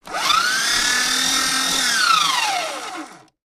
Paper Shredder
Paper shredder destroys documents.